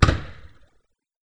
bang.wav